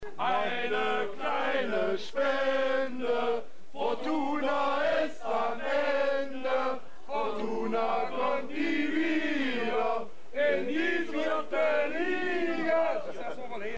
FCM-Fansongs und Blocklieder